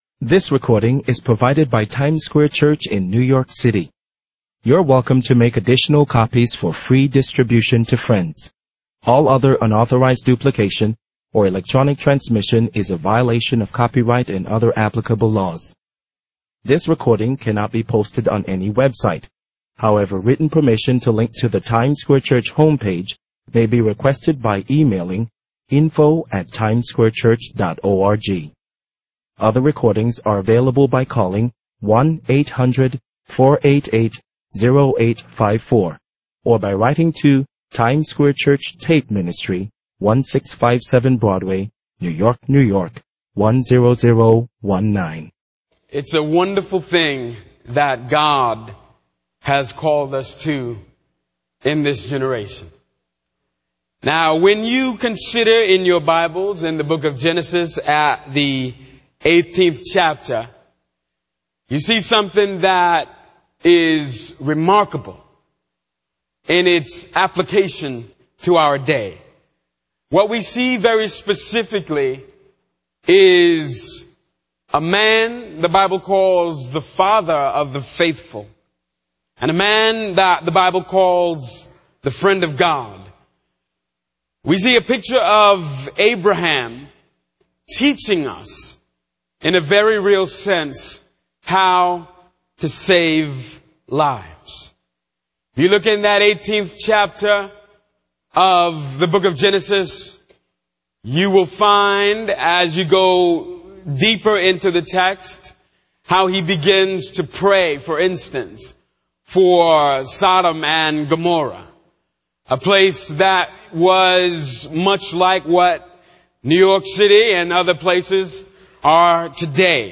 The Friends Of God Pt. 4 | Times Square Church Sermons
Our messages are recorded at Times Square Church in New York City.